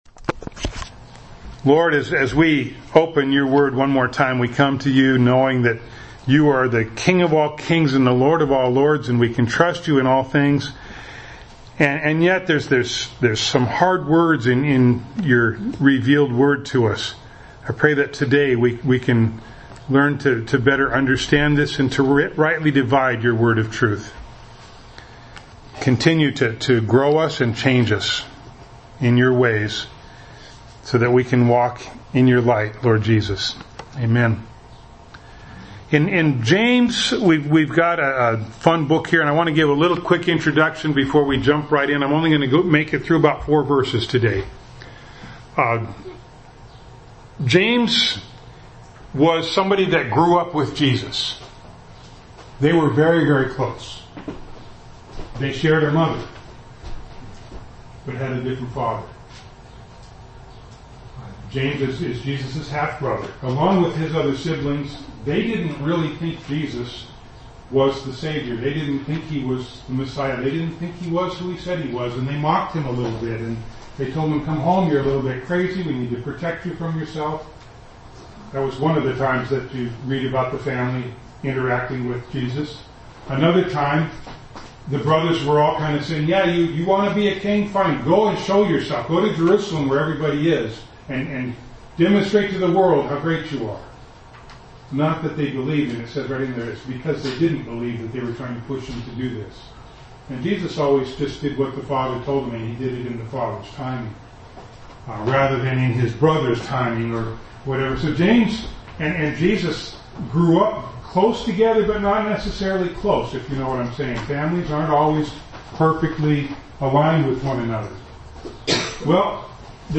James 1:1-4 Service Type: Sunday Morning Bible Text